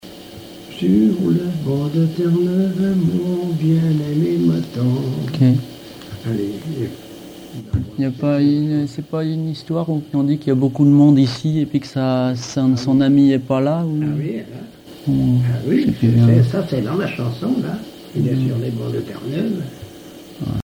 Chansons en dansant
danse : ronde
Pièce musicale inédite